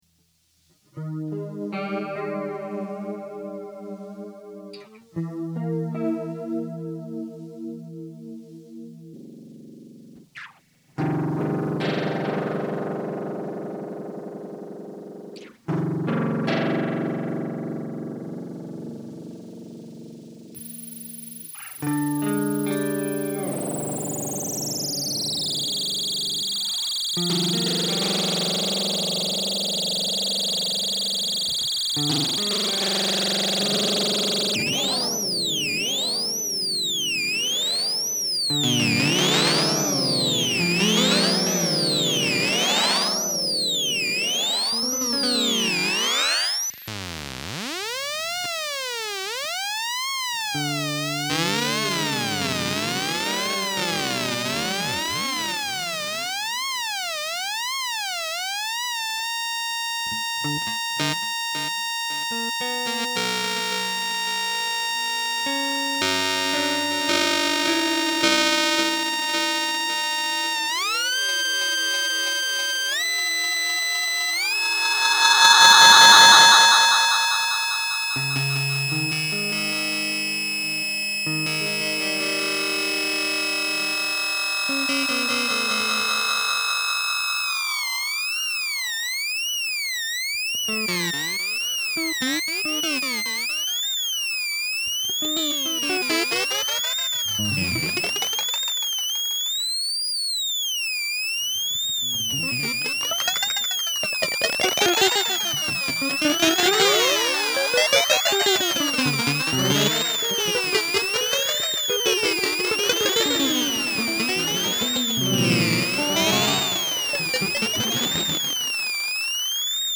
This is an echo pedal-a noisy, sireny, self oscillating, incredibly loud, swirling, underclocked echo pedal. It can't make a nice clean delay sound at all... it can, however, create quite a large wall of noise when self oscillating.
If you flip the depth switch it does some very big pitch shifting-vibrato sounds.